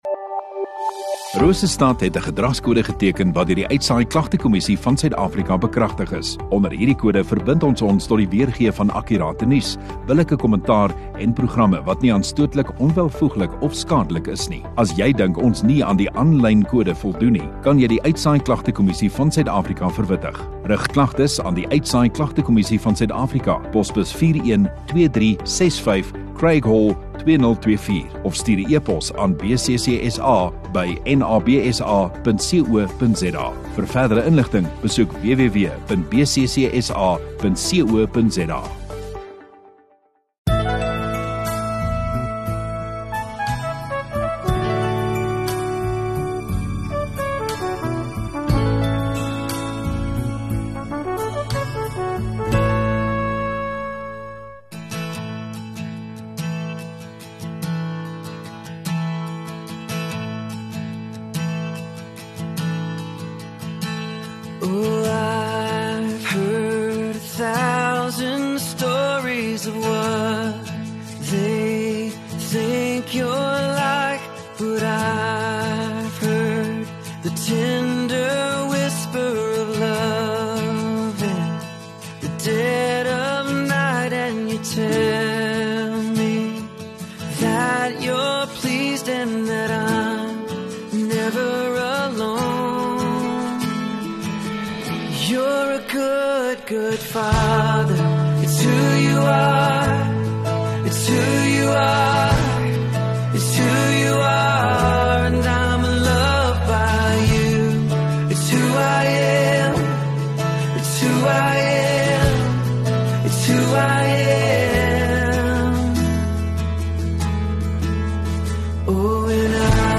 28 Jul Sondagoggend Erediens